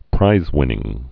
(prīzwĭnĭng)